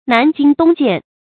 南金東箭 注音： ㄣㄢˊ ㄐㄧㄣ ㄉㄨㄙ ㄐㄧㄢˋ 讀音讀法： 意思解釋： 南方的金石，東方的竹箭。